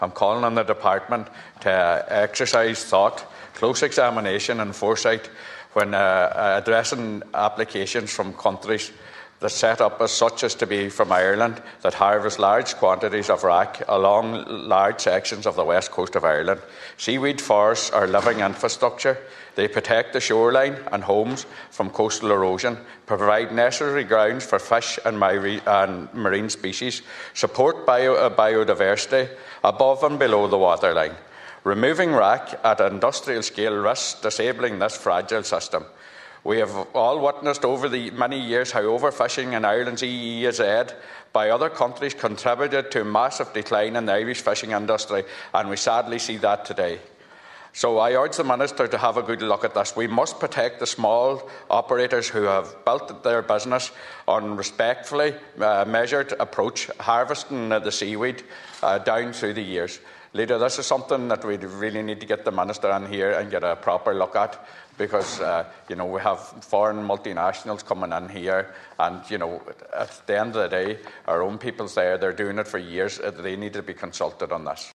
In the Seanad, Donegal Senator Manus Boyle said traditional harvesters fear handing over seaweed rights to large corporations would leave communities with no input into how their land and environment is treated.